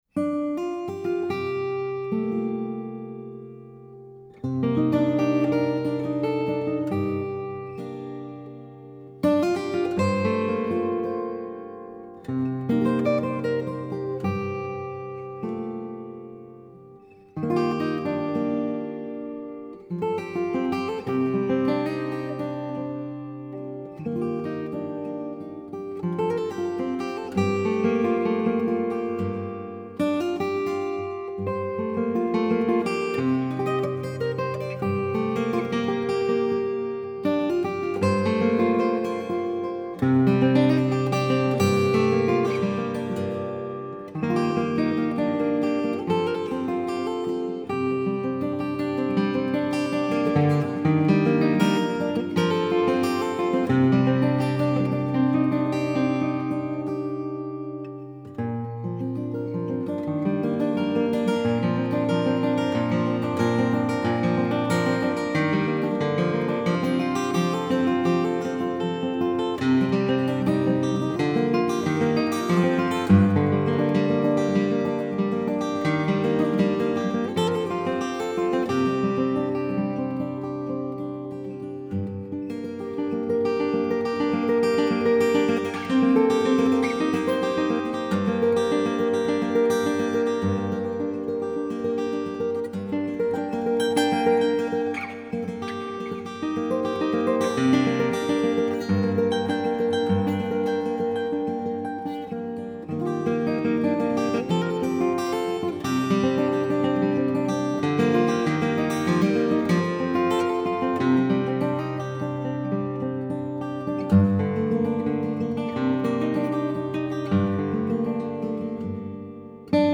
Recording and Concert Model
With light gauge strings, a guitar that produces big, full, rich sounds that were never before possible, even with medium strings.